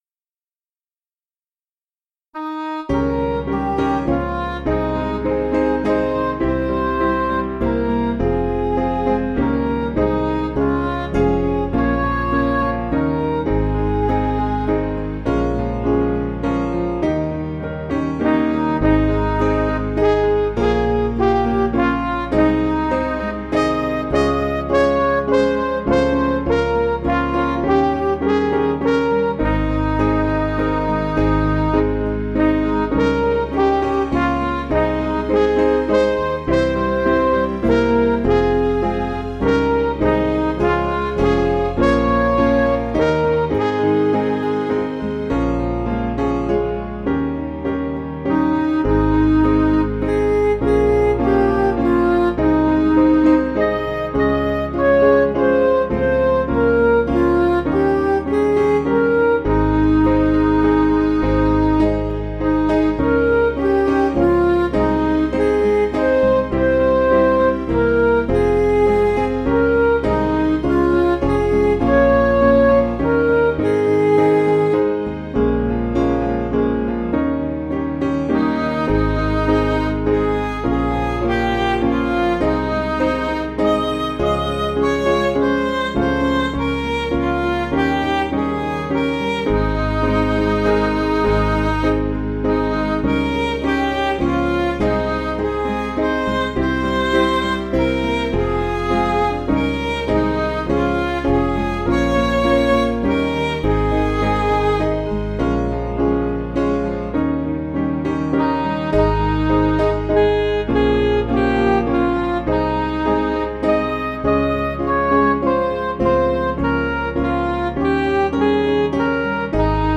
Piano & Instrumental
(CM)   5/Ab